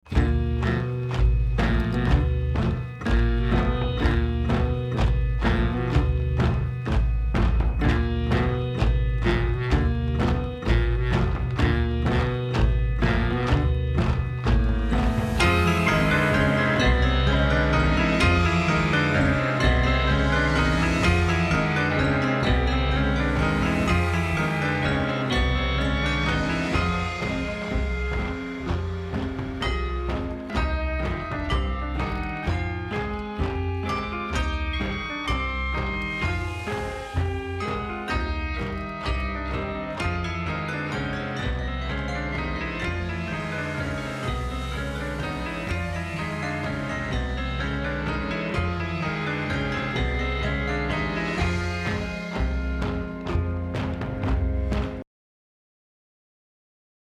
Mad Hysteria Vaudeville Chorus Soundbed (Track 03.mp3)